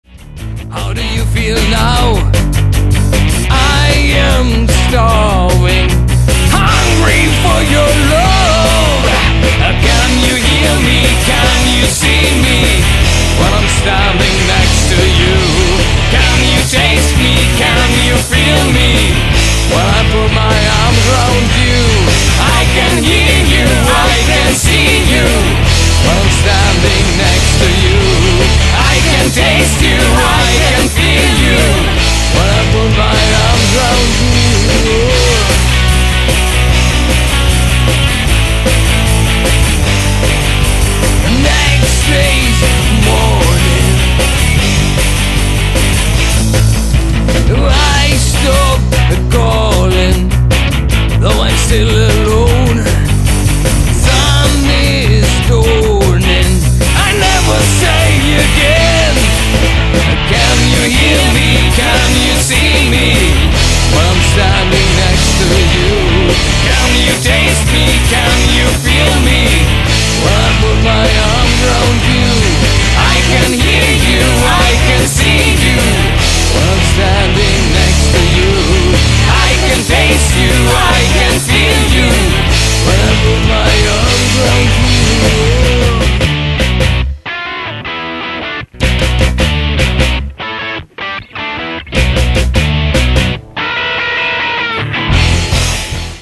3+1+1 members (trio + additional female backing vocals)
DRUMS
VOX, BASS
backing VOX
Demo Songs